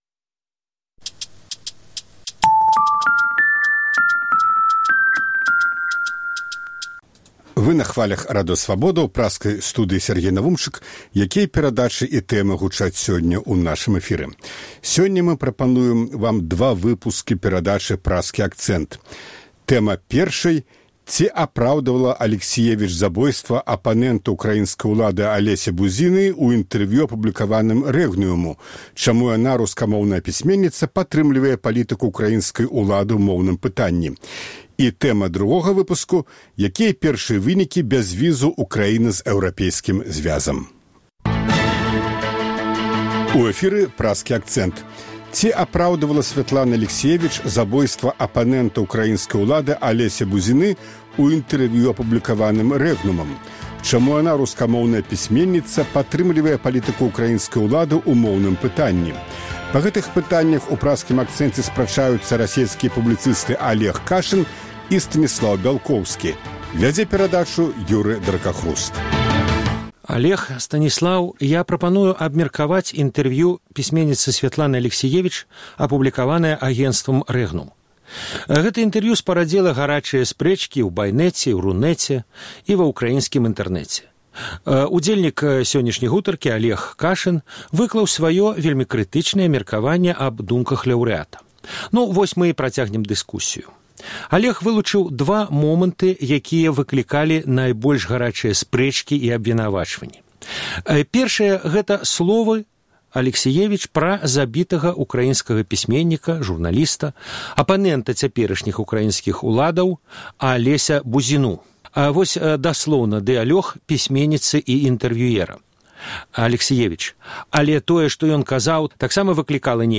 Па гэтых пытаньнях ў Праскім акцэнце спрачаюцца расейскія публіцысты Алег Кашын і Станіслаў Бялкоўскі.